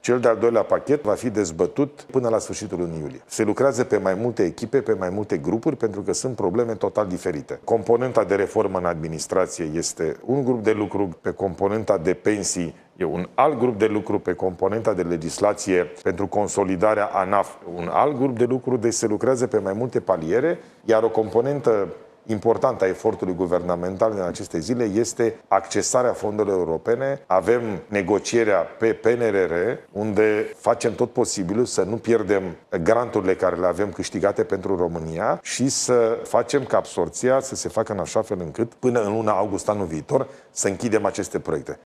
Măsurile includ reforme ce vizează pensiile speciale, companiile de stat, administraţia centrală şi locală. Premierul Ilie Bolojan a declarat, aseară, la un post de televiziune că o altă prioritate este atragerea fondurilor europene: